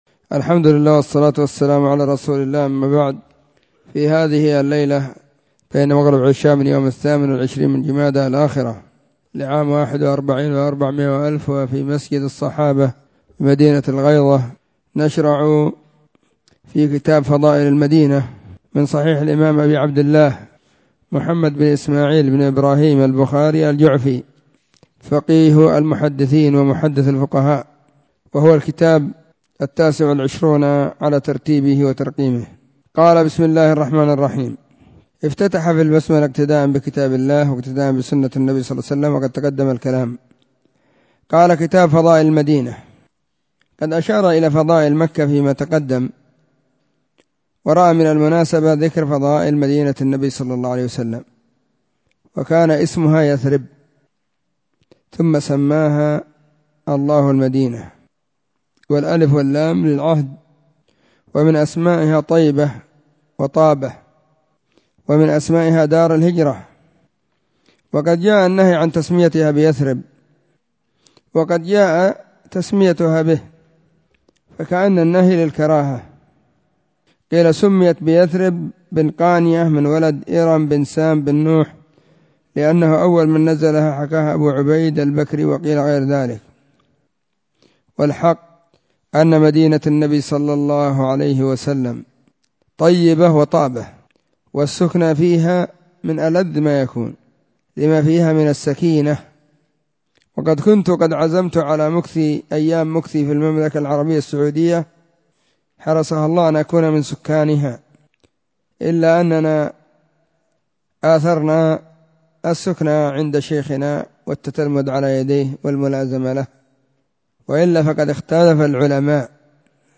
صحيح البخاري 532كتاب فضائل المدينة الدرس 01
🕐 [بين مغرب وعشاء – الدرس الثاني]